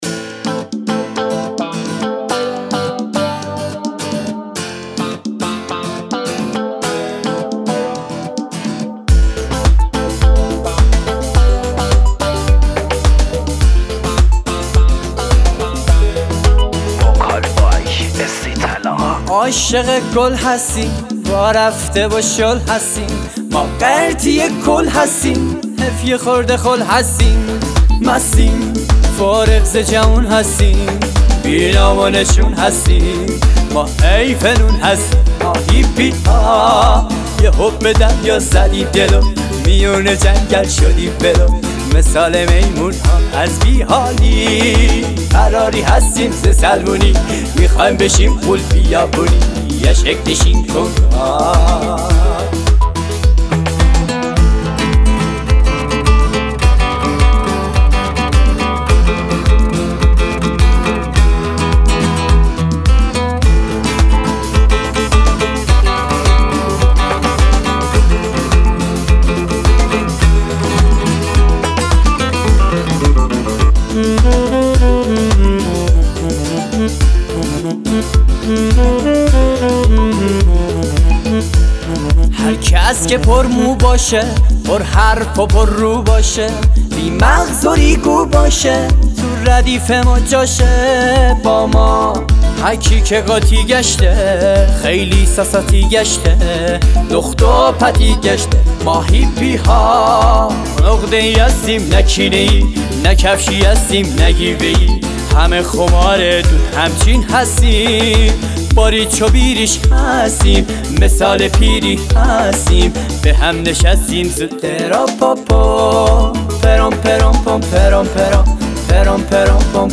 آهنگ آذری و ترکی